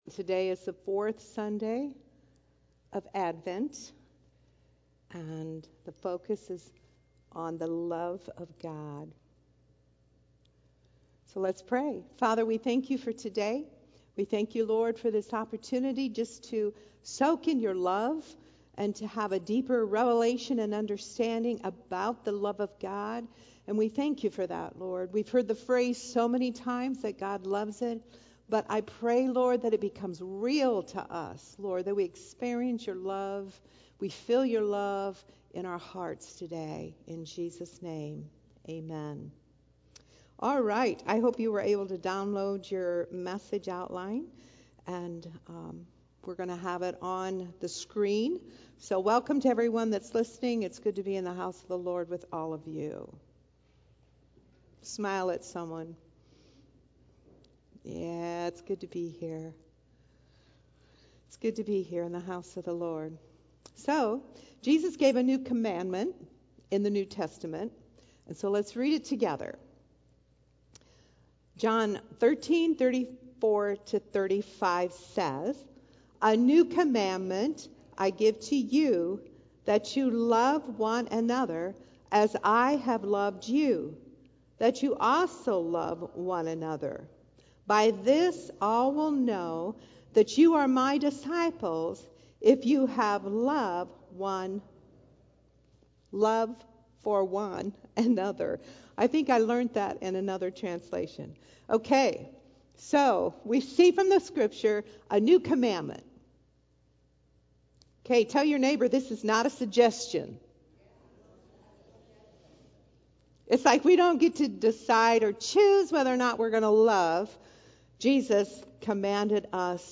Sermons Archive - Page 24 of 47 - River of Life Community Church